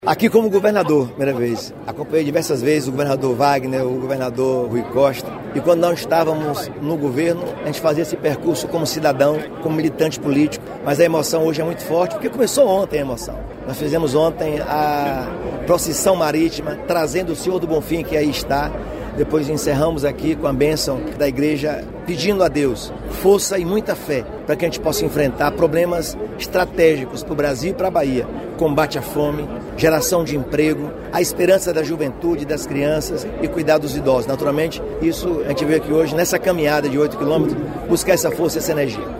Ao participar nesta quinta-feira (12) da tradicional Lavagem do Bonfim, o governador Jerônimo Rodrigues falou da emoção de participar do cortejo como governador.